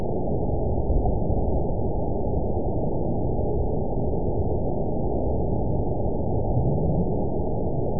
event 922860 date 04/23/25 time 04:15:27 GMT (1 week, 1 day ago) score 8.55 location TSS-AB02 detected by nrw target species NRW annotations +NRW Spectrogram: Frequency (kHz) vs. Time (s) audio not available .wav